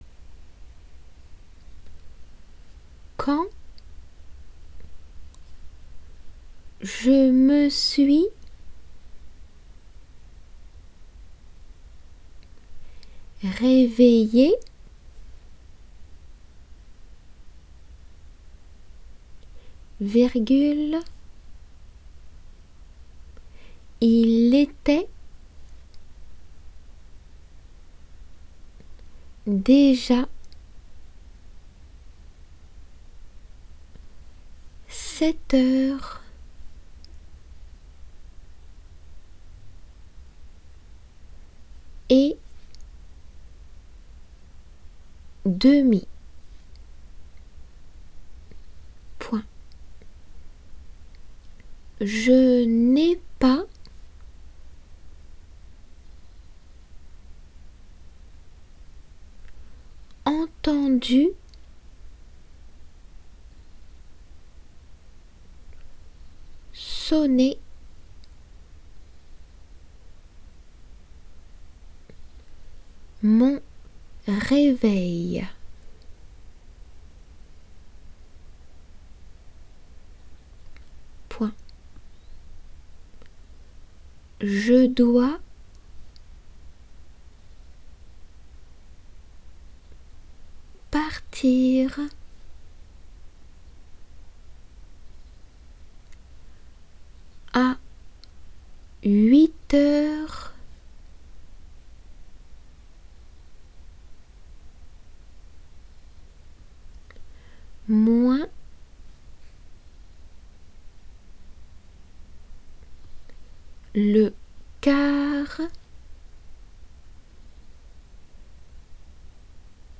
デイクテのl速さで。